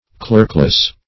clerkless - definition of clerkless - synonyms, pronunciation, spelling from Free Dictionary Search Result for " clerkless" : The Collaborative International Dictionary of English v.0.48: Clerkless \Clerk"less\, a. Unlearned.